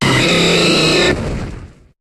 Cri de Braségali dans Pokémon HOME.